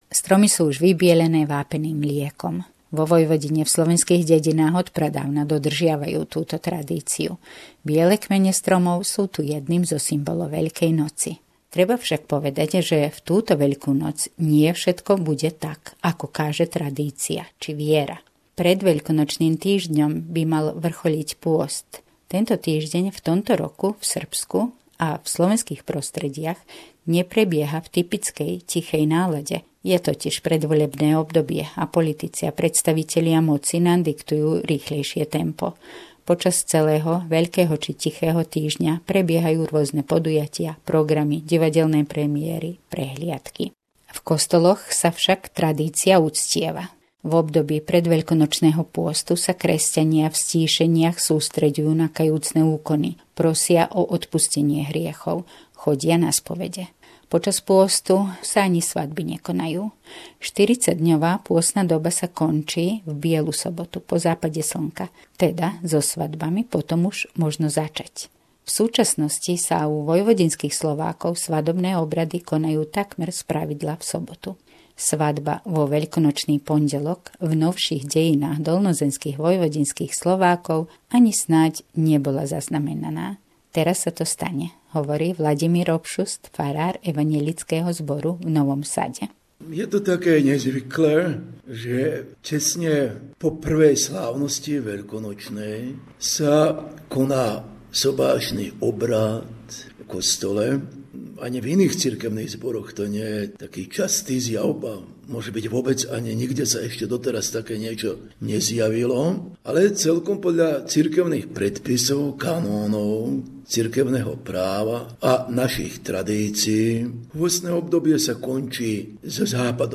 Veľkonočná zvuková pohľadnica od našej kolegyne vo Vojvodine